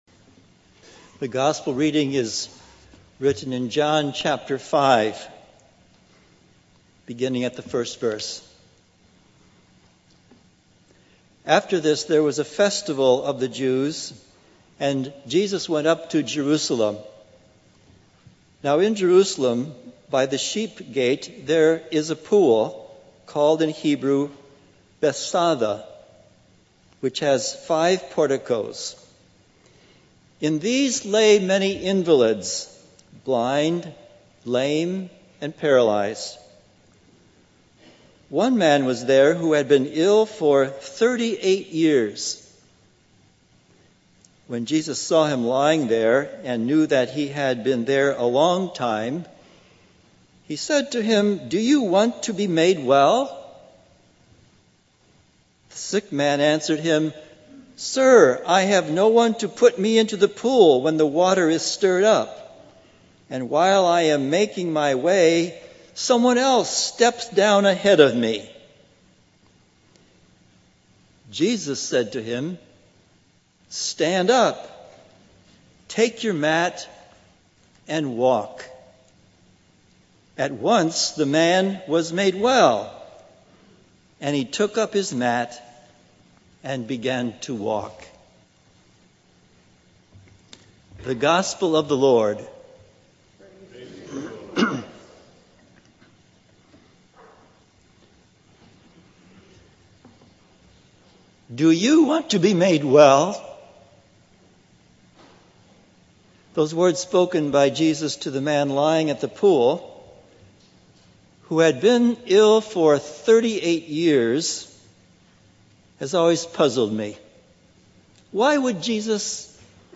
Sermons and Anthems | The Second Reformed Church of Hackensack